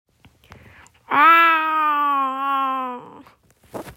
猫の真似